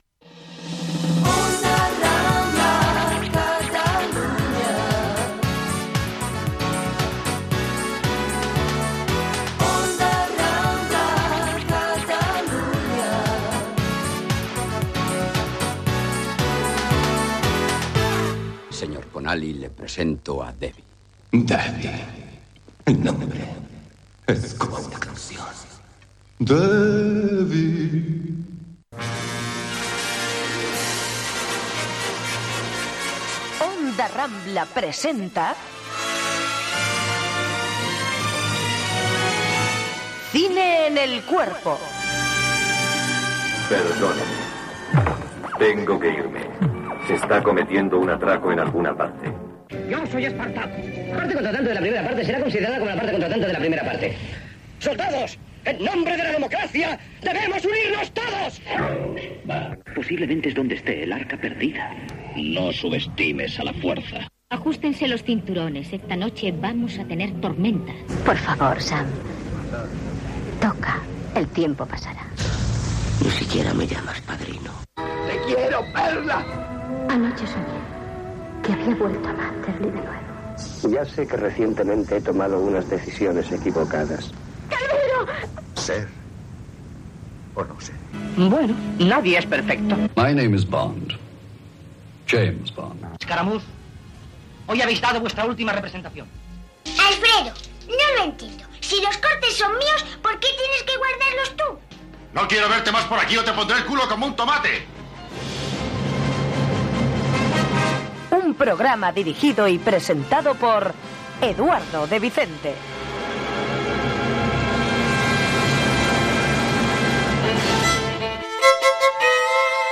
Indicatiu de l'emissora, careta del programa, presentació, equip i tema musical